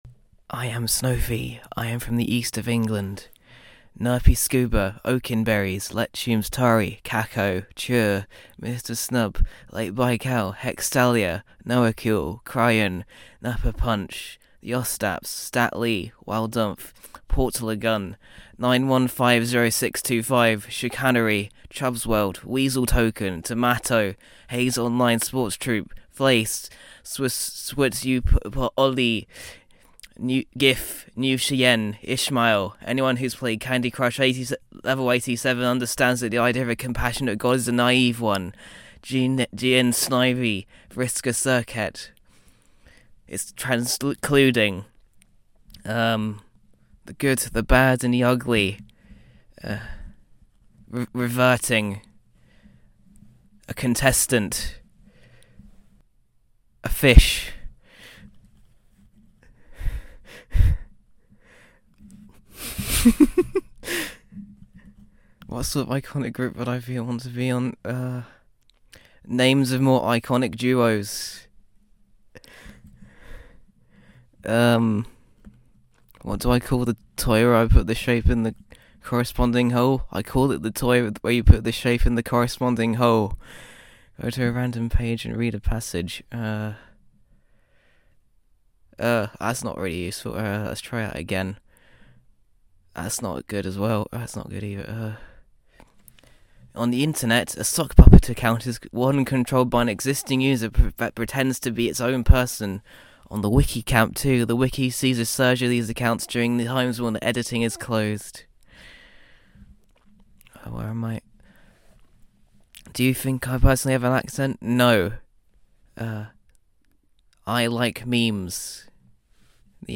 Recordings of ourselves answering those questions.